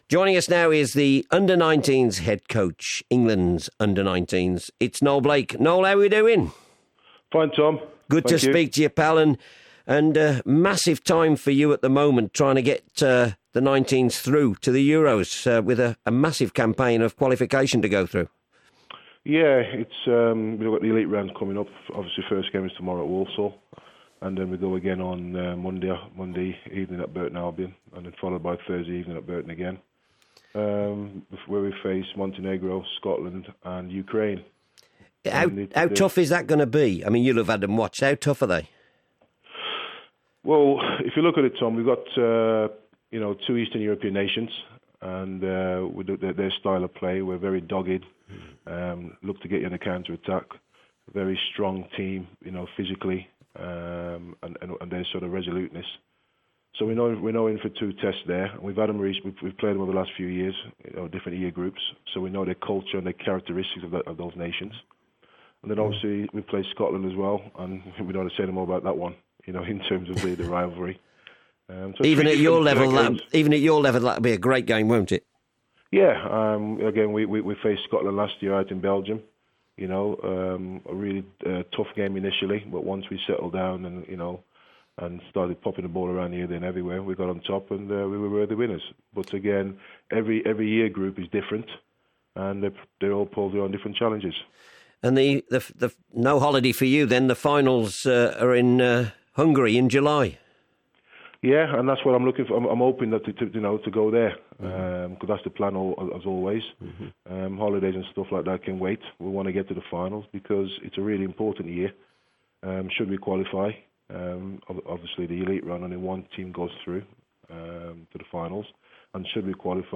has a chat